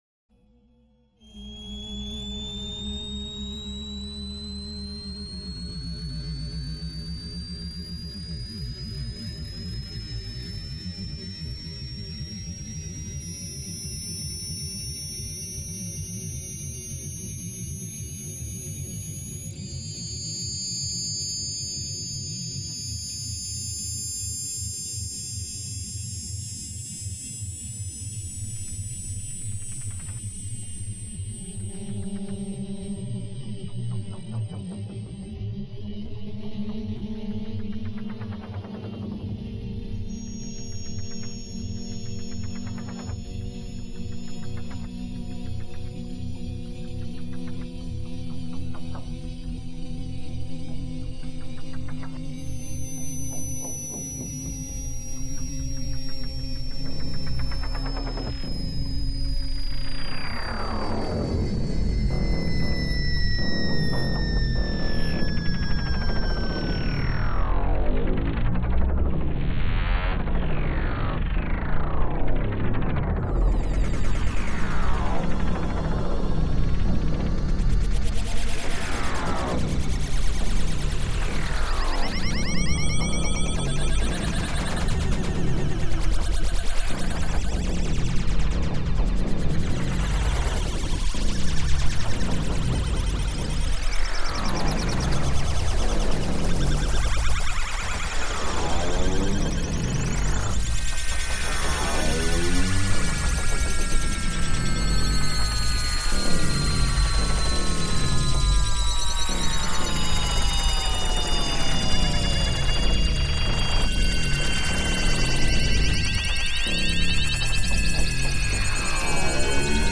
L'apparition et l'utilisation quotidienne des outils numériques consacrés aux sons m'a tout naturellement dirigé/amené à la musique acousmatique.
Réalisée en 2011 durant le festival FIMAV de Victoriaville au Québec.